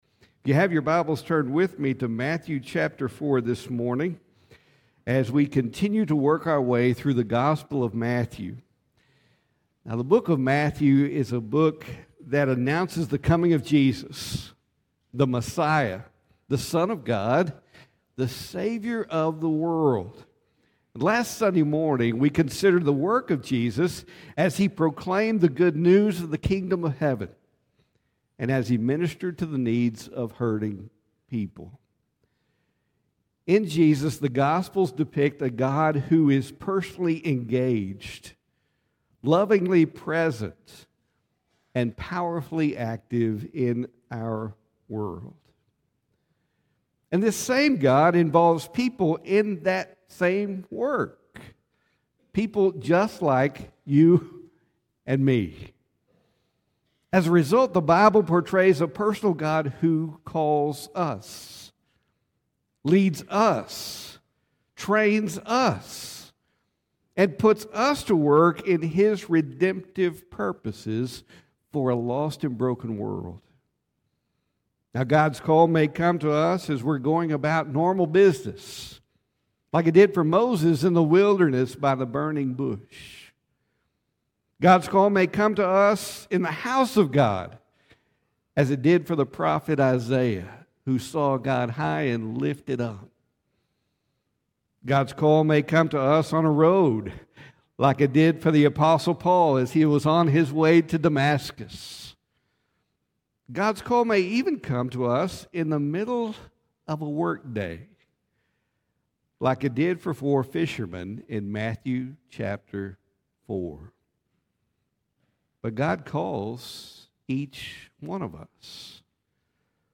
Sermons | First Baptist Church Brownwood